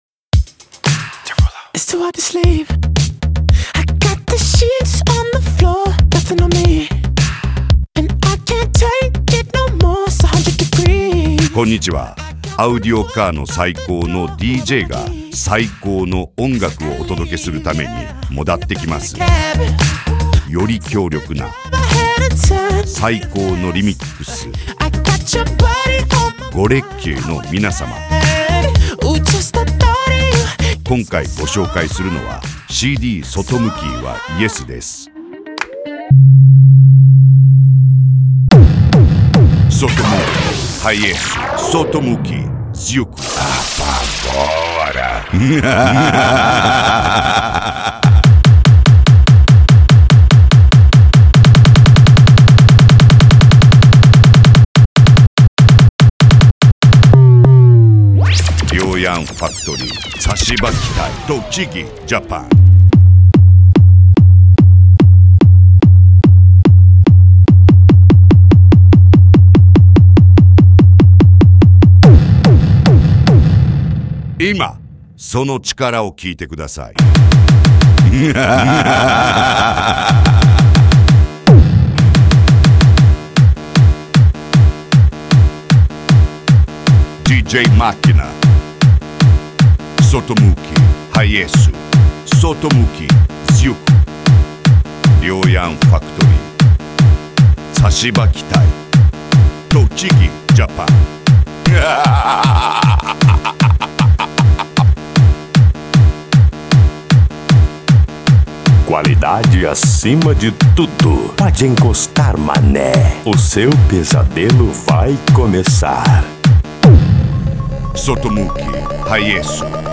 Eletronica
japan music
PANCADÃO